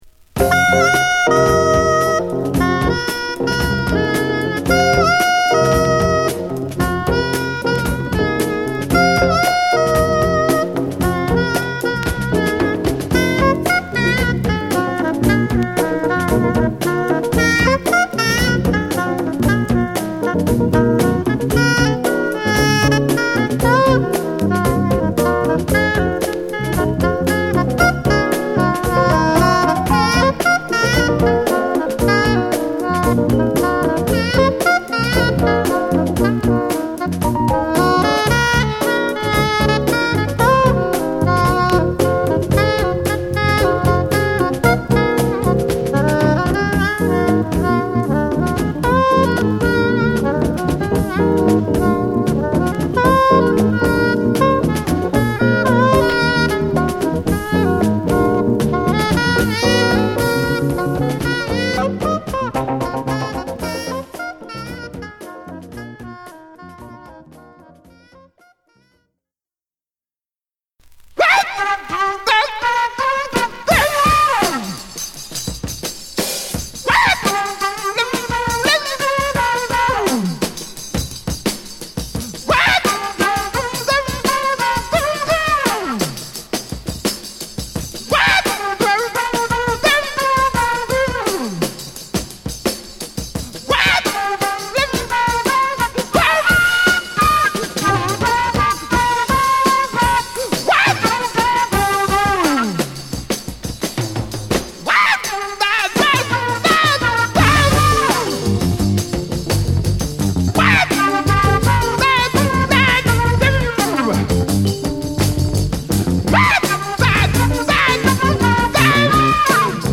ファンキーなフルートとスキャットから始まるキラーなJazzFunkで、中盤からのメロウなエレピ等もサイコーな1曲！
文句無しのJazz Funk名盤です！